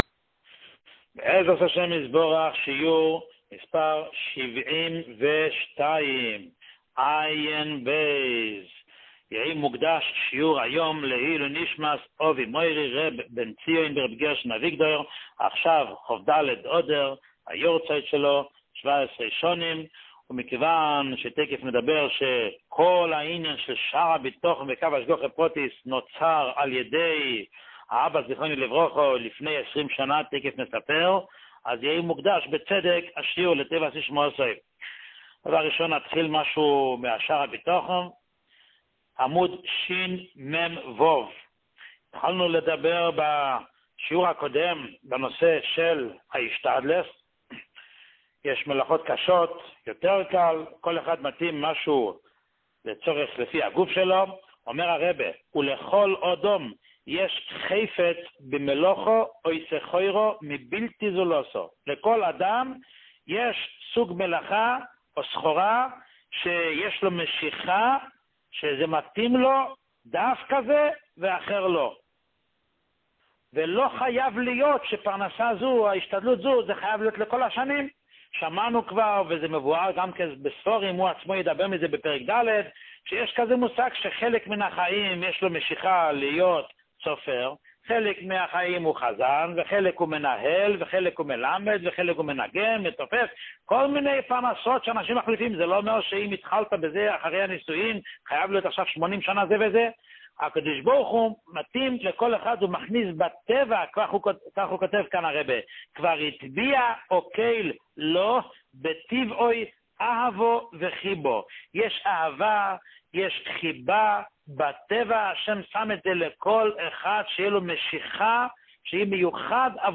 שיעור 72